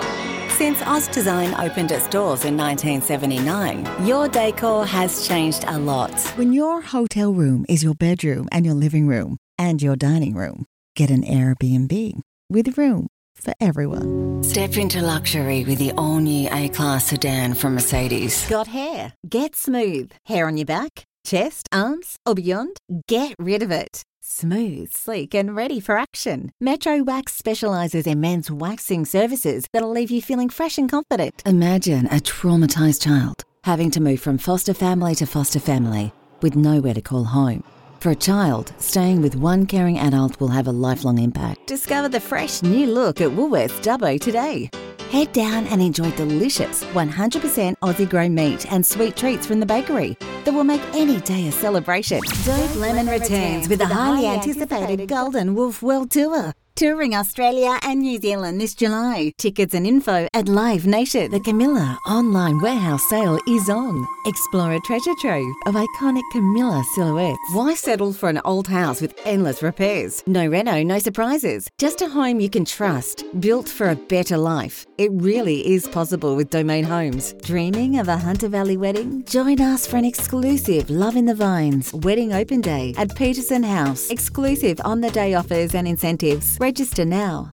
I have a Bright, corporate young adult to middle-aged sound. I am conversational, believable, yet authoritative, down to earth and casual.
My voice is warm, trustworthy natural, sincere, authentic, versatile and can also be described as authoritative.